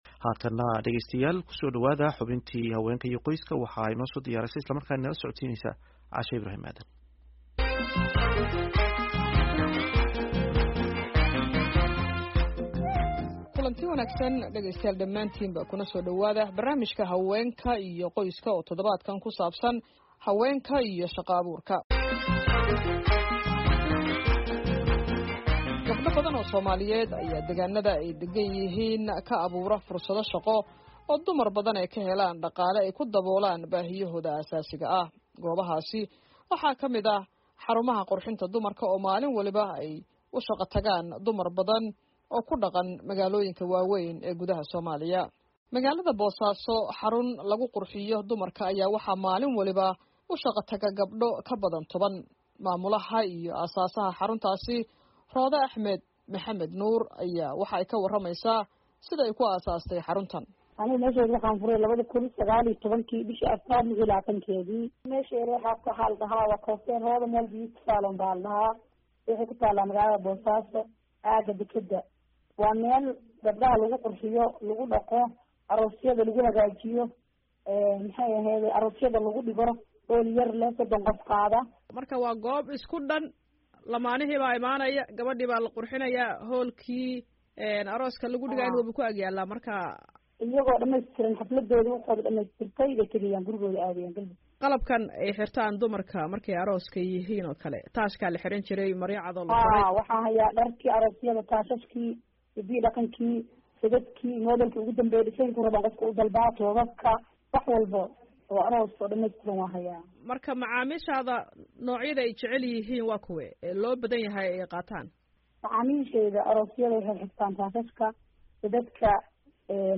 VOA STUDIO